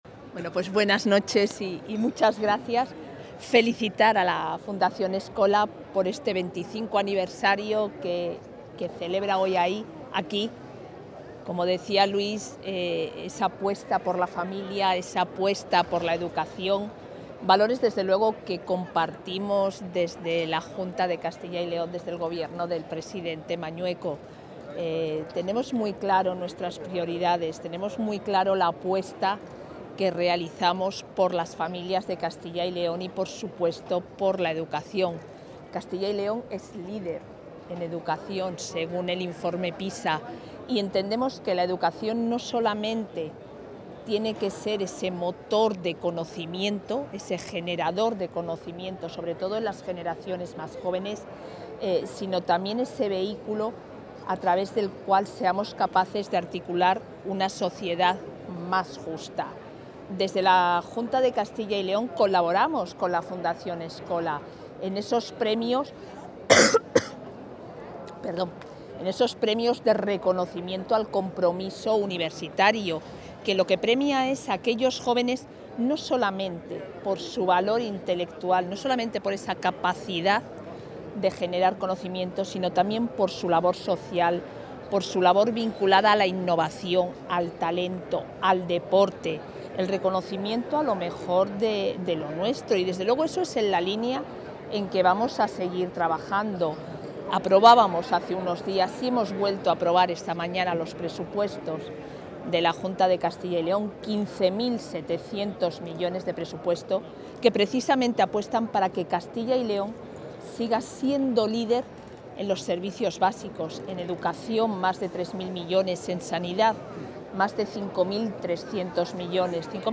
La vicepresidenta de la Junta participa en la celebración del XXV aniversario de Fundación Schola
Declaraciones de la vicepresidenta.